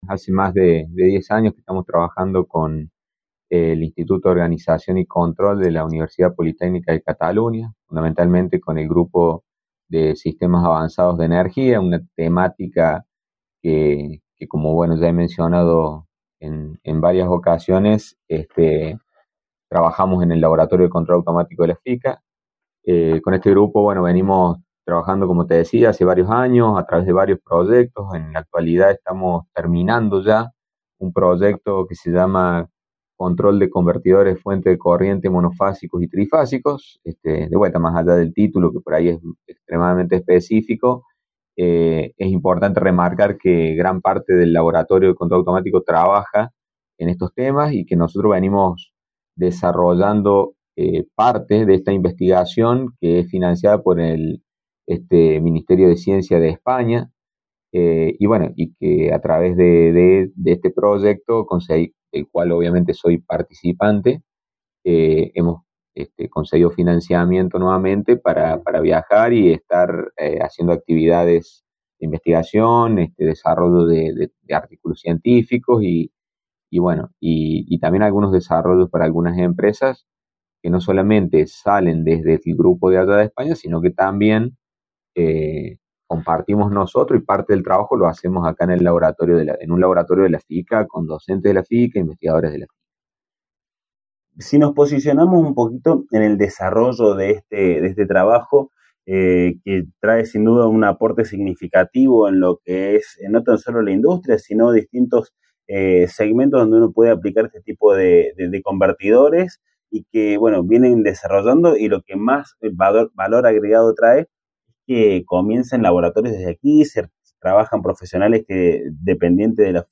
En diálogo con Radio UNSL Villa Mercedes 97.5 FM